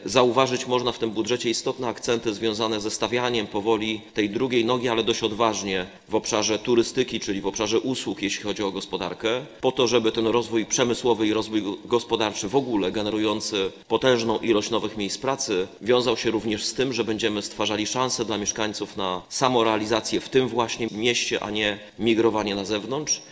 Prezydent Rafał Zając zaznaczał podczas dzisiejszej sesji Rady Miejskiej, że widać z nim pewną zmianę w myśleniu o przyszłości miasta.